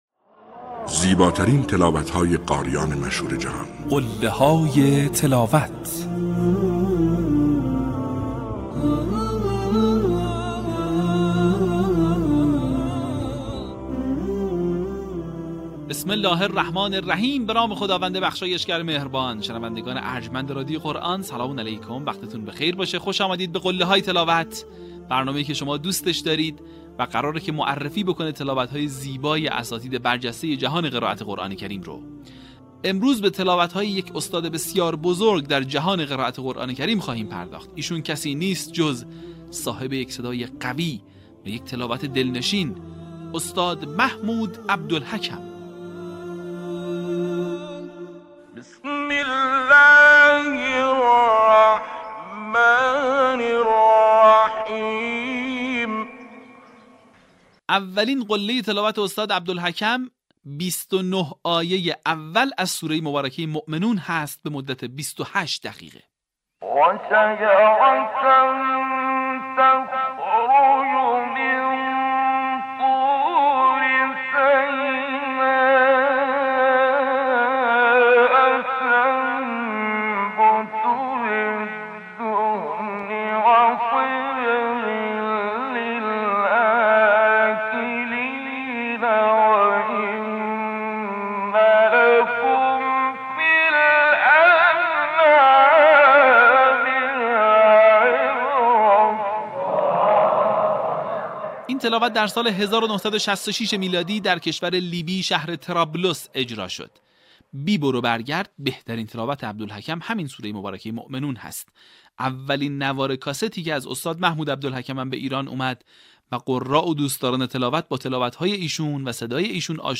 این مجموعه شامل تلاوت‌های ماندگار قاریان بین‌المللی مصری است که تاکنون 40 قسمت آن از رسانه ایکنا منتشر شده است.